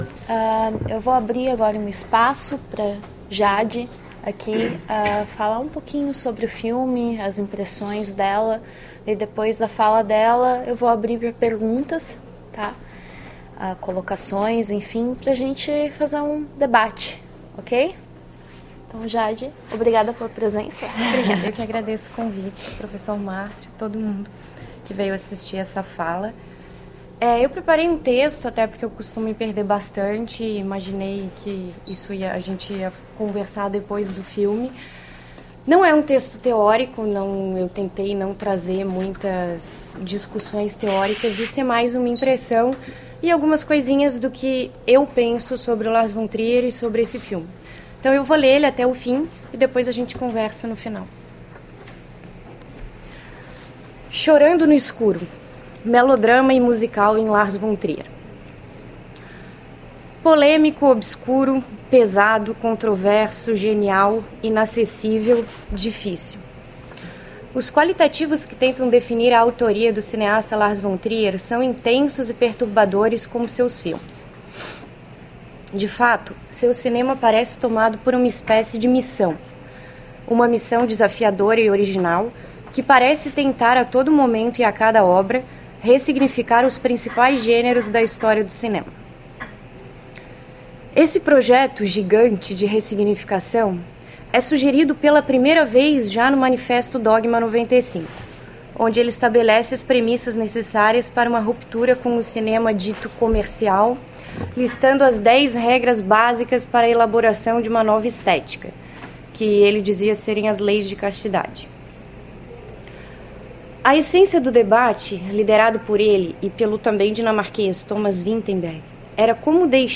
Comentários do filme "Dançando no Escuro"
na sessão de exibição e debate do filme "Dançando no Escuro", (Dancer in the Dark, ano de produção: 2000), do diretor Lars von Trier, realizada em 28 de agosto de 2014 no Auditório "Elke Hering" da Biblioteca Central da UFSC.